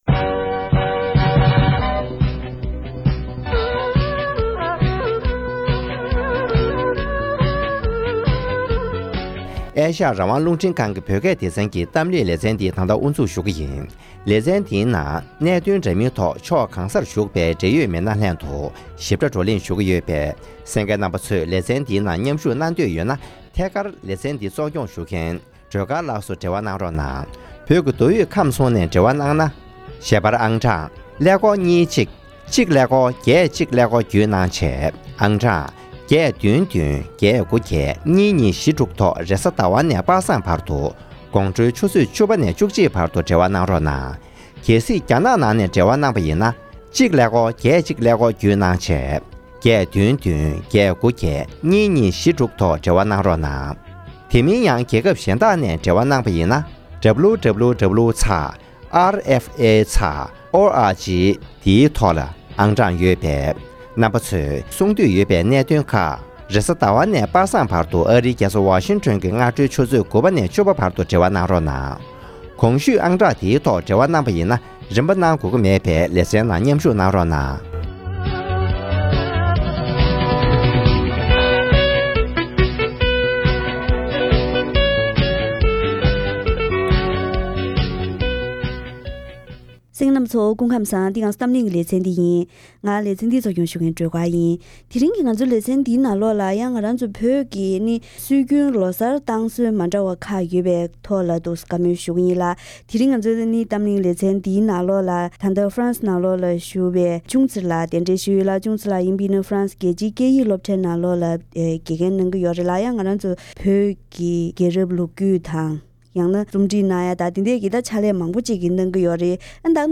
༄༅། །ཐེངས་འདིའི་གཏམ་གླེང་ལེ་ཚན་ནང་།